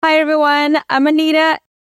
Genre: Blues.